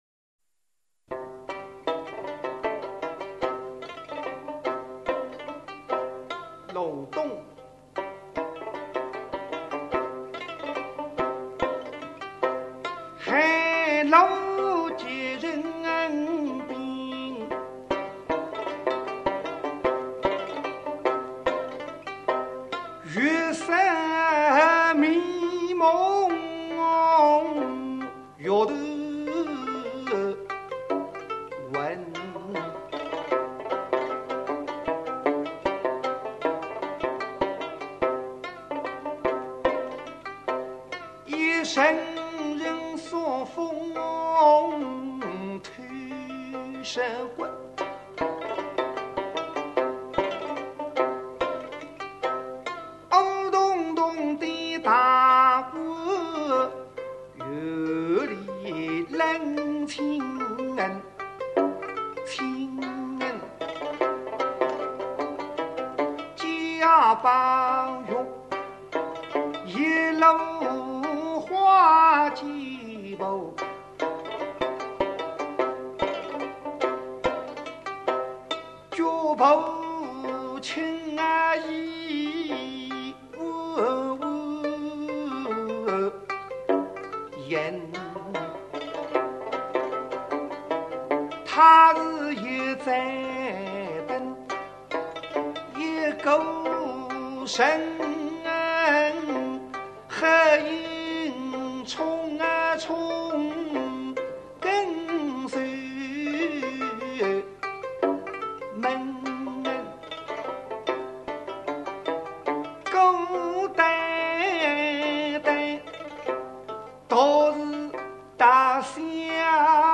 评弹，乃发源于苏州的曲艺品种，包括只说不唱的平评话及有说有唱的弹词，故称评弹。
弹唱在弹词中占相当重要的比重。